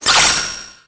Cri de Meltan dans Pokémon Épée et Bouclier.